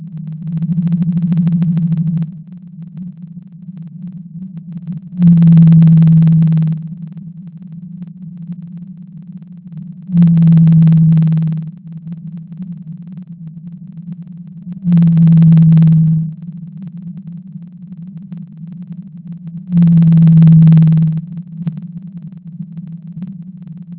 Blue_Whale_NE_Pacific.ogg